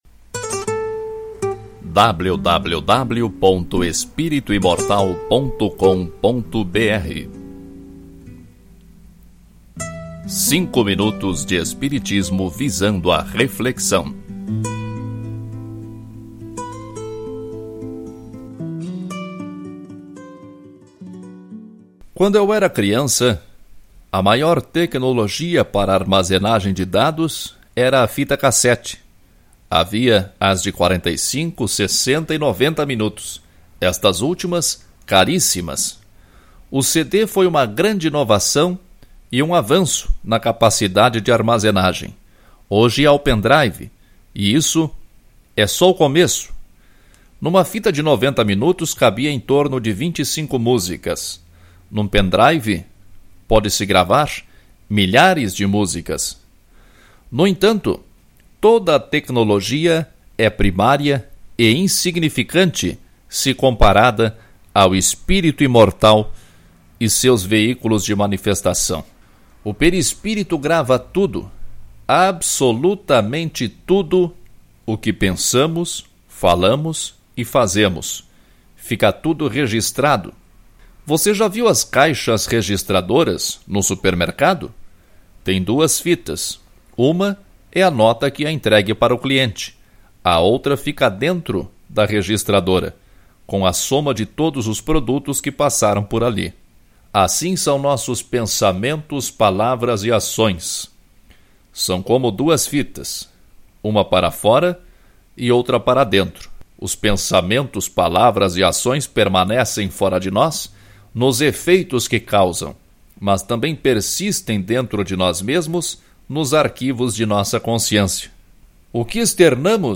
Ouça este artigo na voz do autor